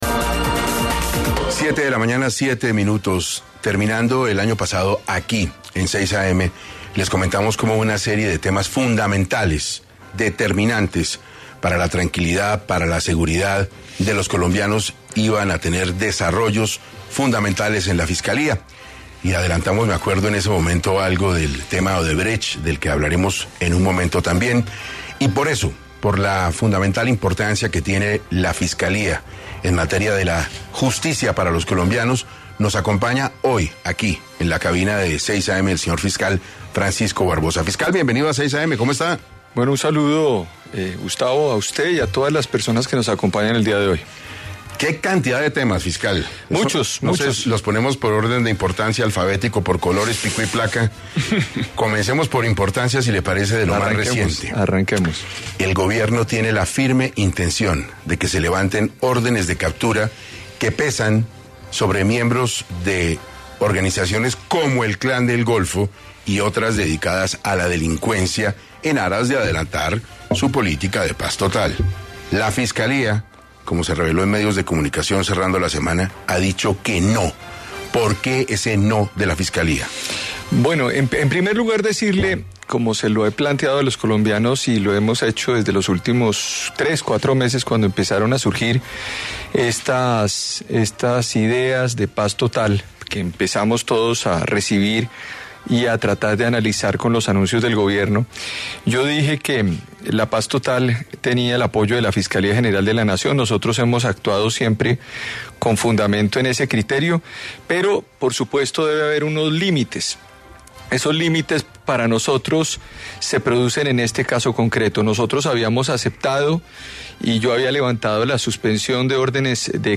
El fiscal General de la Nación, Francisco Barbosa, en diálogo con 6Am Hoy por Hoy de Caracol Radio, se refirió al tema y aseguró que la entidad actuó bajo las normas que establece la Constitución y que tienen que haber límites para la denominada ‘paz total’.